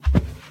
Sound / Minecraft / mob / cow / step4.ogg
should be correct audio levels.